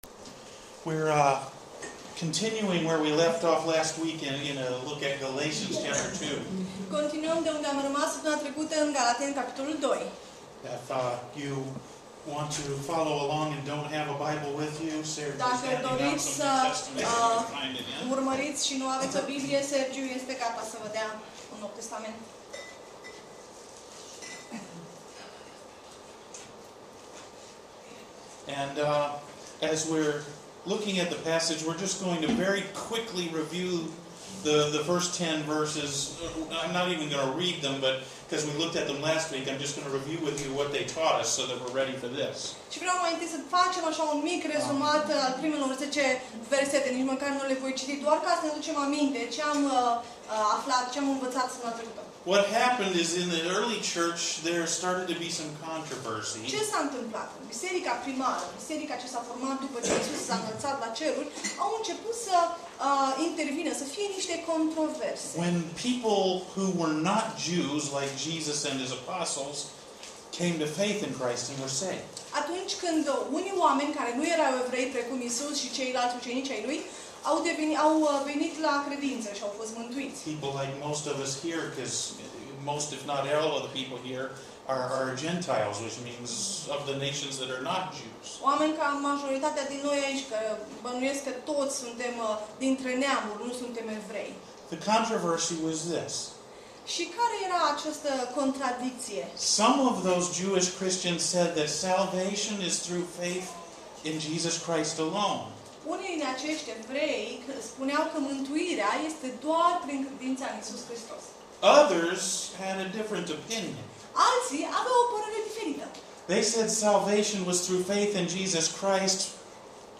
Last Sunday’s message at the church in Cluj looked at that issue through Paul’s teaching in Galatians 2. You can listen to the recording, in English and Romanian at the link to the church site here.